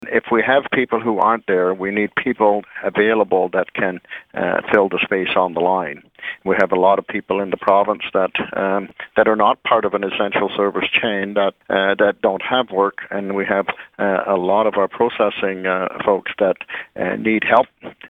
Hardeman says the goal of the portal is to link workers with employers, especially at a time of higher demand, and employee illness.